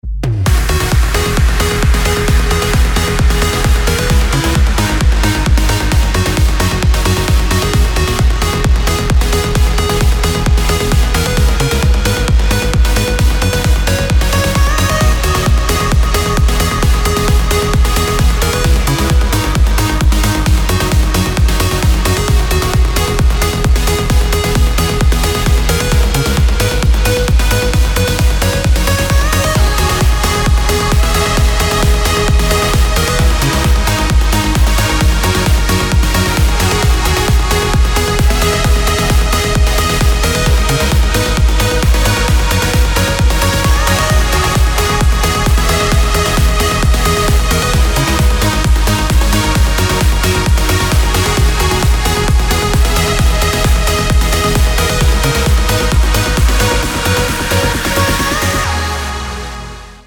• Качество: 256, Stereo
громкие
dance
Electronic
без слов
club
Trance
Uplifting trance
Epic Trance